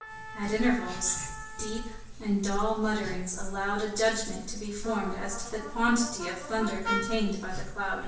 SpatializedLibriTrans/cv/mixture/mix_1024.wav · espnet/Libri-Trans-Spatialized_SLURP-Spatialized_dataset at main